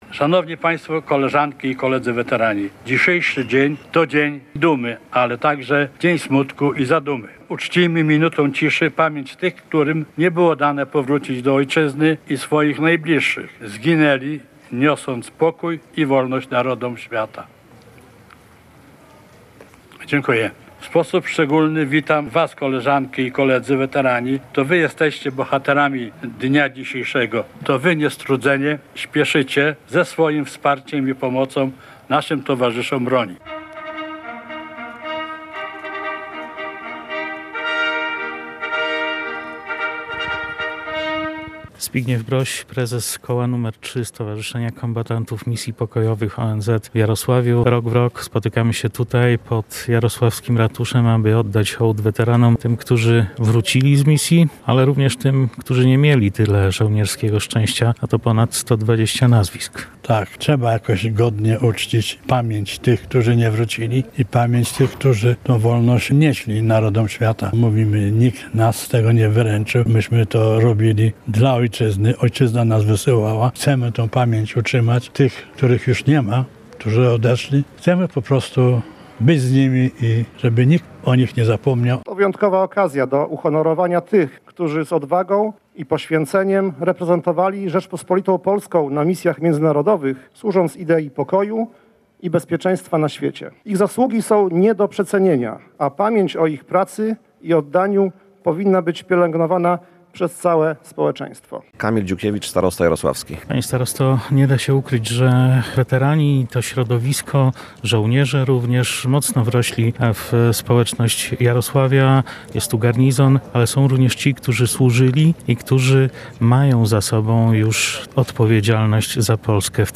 Podczas uroczystości w Jarosławiu Apelem Pamięci uczczono żołnierzy, którzy uczestniczyli w misjach i podczas udziału w ich ponieśli śmierć. Uroczystość zakończyły Salwa Honorowa, oraz złożenie pod tablicą upamiętniającą udział żołnierzy Garnizonu Jarosław w misjach pokojowych wieńców i wiązanek kwiatów.